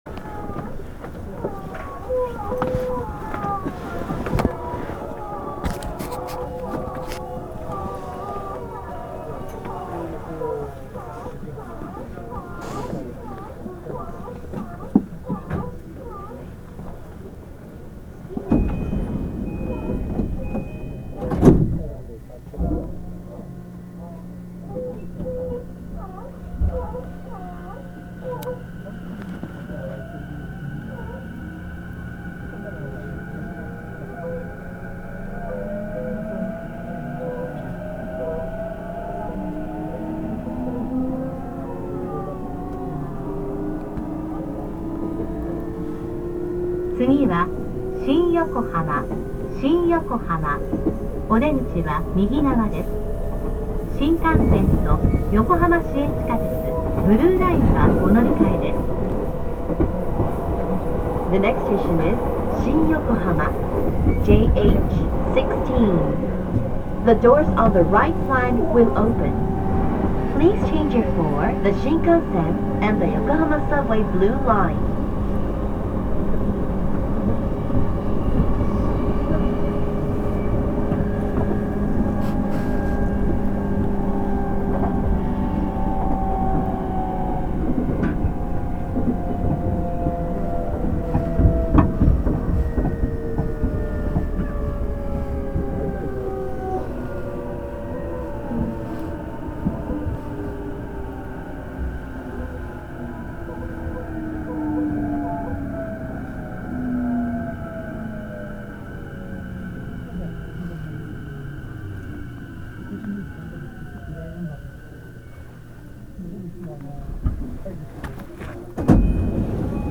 走行音
録音区間：菊名～新横浜(お持ち帰り)